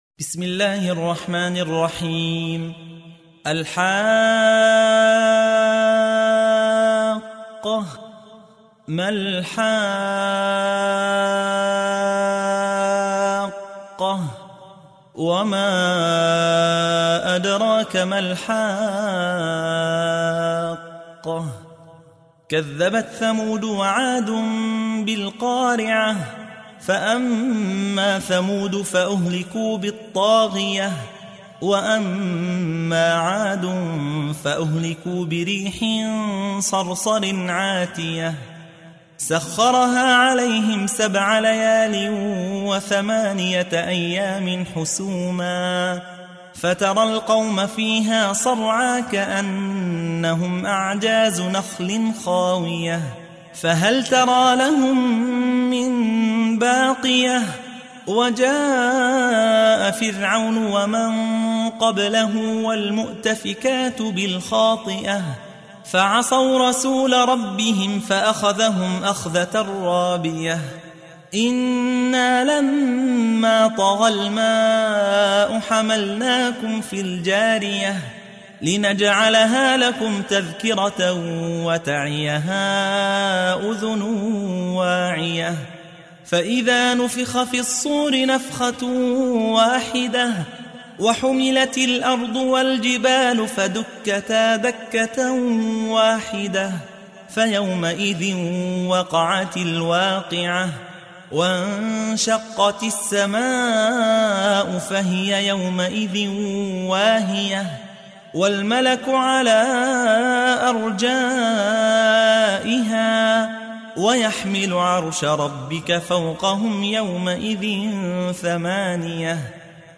تحميل : 69. سورة الحاقة / القارئ يحيى حوا / القرآن الكريم / موقع يا حسين